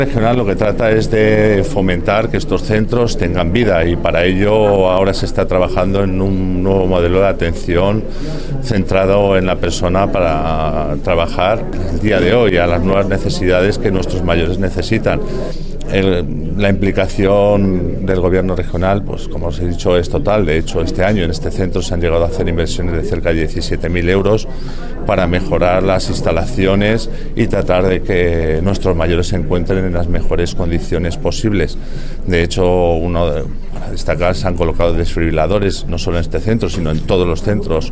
El director provincial de Bienestar Social en Guadalajara, José Luis Vega, habla del nuevo modelo de trabajo en los centros de mayores en el que trabaja el Gobierno regional.